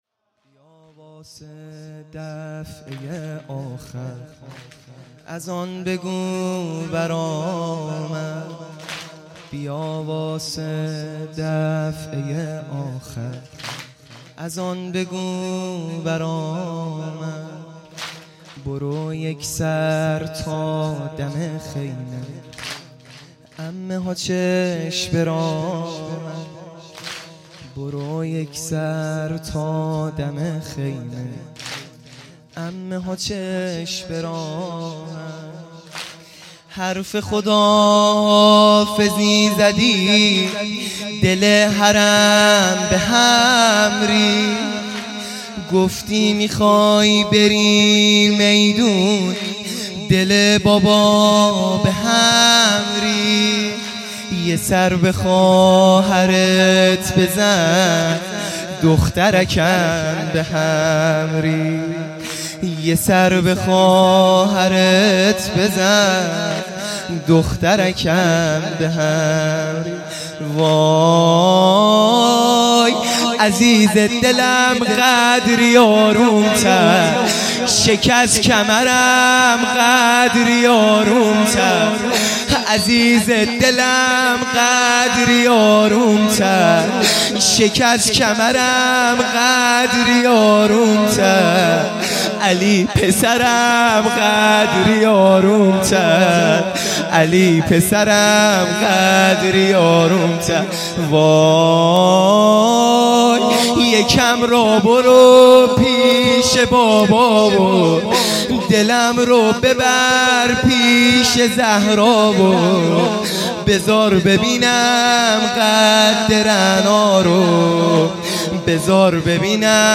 شب هشتم محرم الحرام ۱۳۹۶